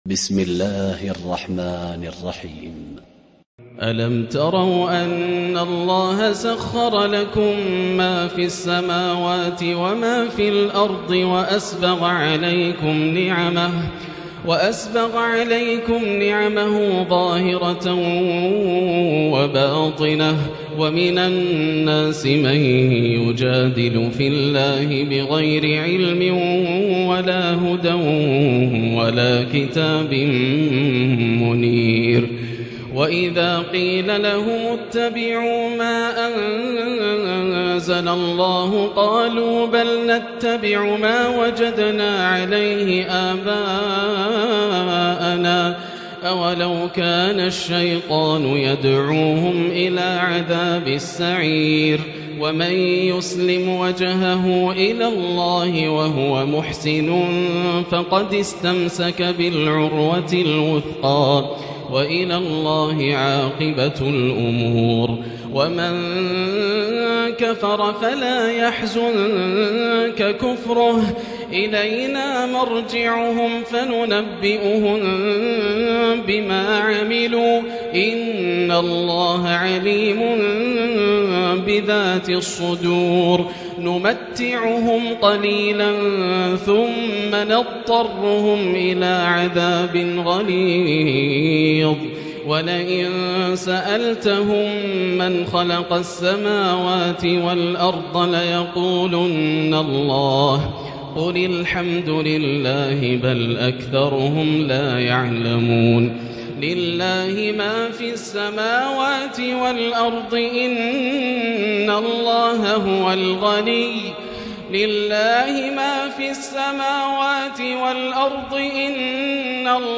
الليلة العشرون - ما تيسر من سورة "لقمان" من آية 20 وسورة السجدة وما تيسر من سورة "الأحزاب" حتى آية 27 > الليالي الكاملة > رمضان 1438هـ > التراويح - تلاوات ياسر الدوسري